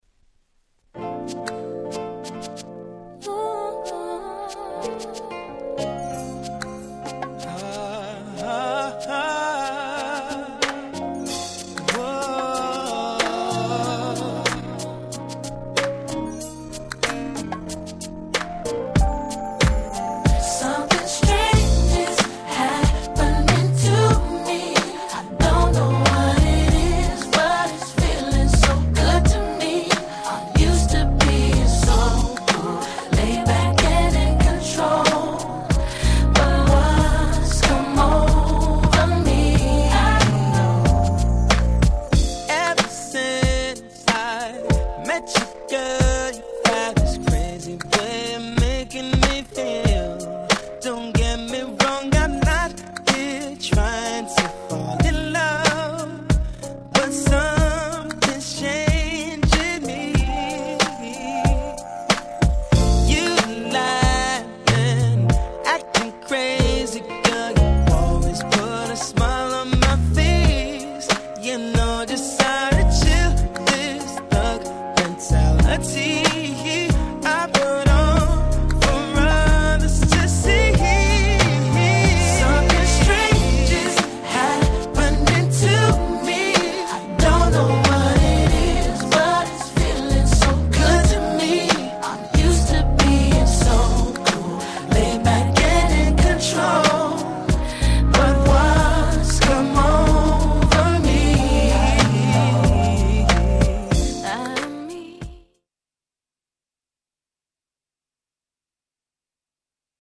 Nice Neo Soul♪